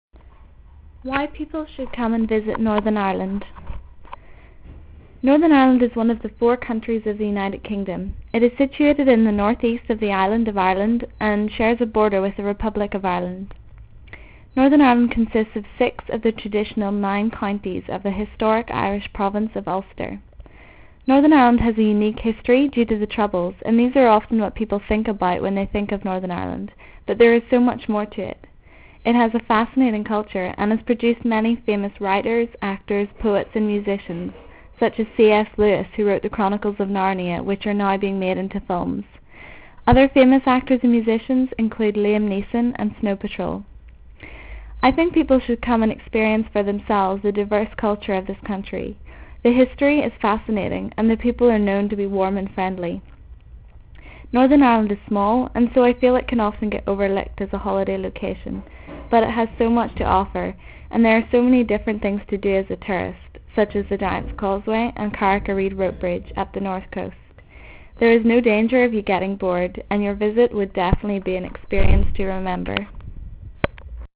Accent
Irlande du Nord